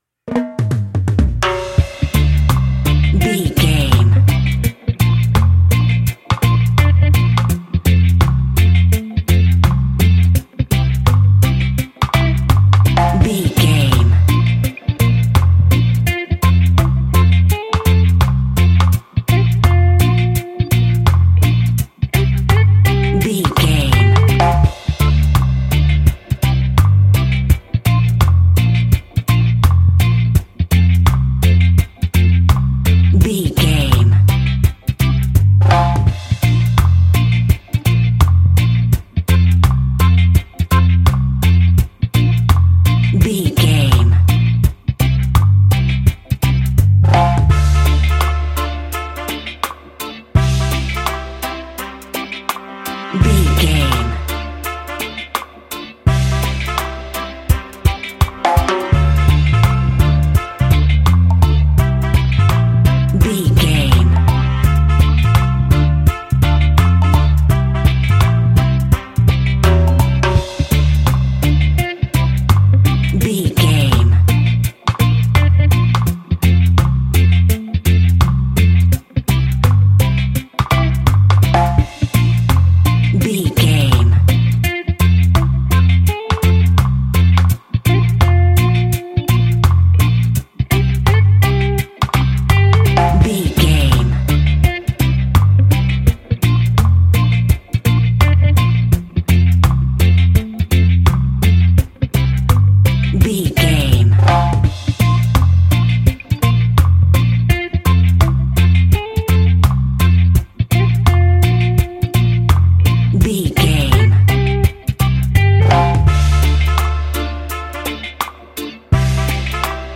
Classic reggae music with that skank bounce reggae feeling.
Aeolian/Minor
dub
reggae instrumentals
laid back
chilled
off beat
drums
skank guitar
hammond organ
transistor guitar
percussion
horns